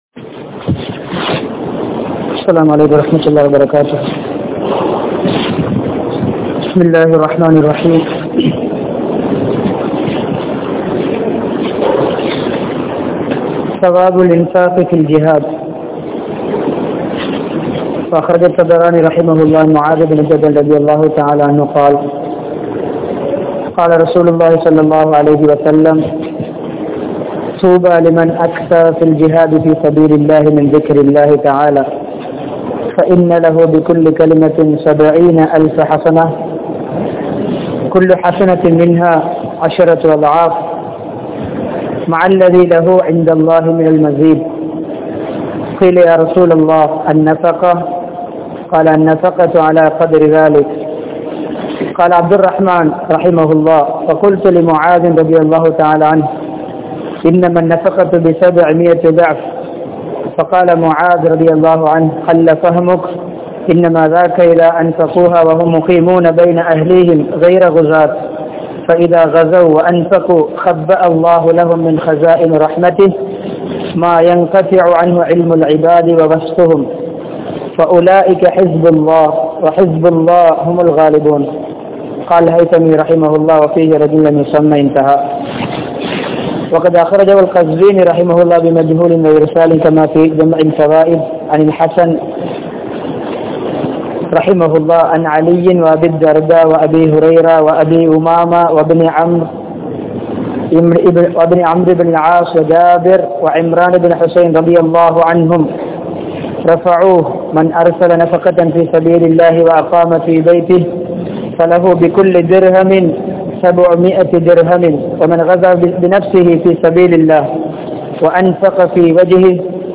Mahaththaana Kooli Veanduma? (மகத்தான கூலி வேண்டுமா?) | Audio Bayans | All Ceylon Muslim Youth Community | Addalaichenai
Colombo 03, Kollupitty Jumua Masjith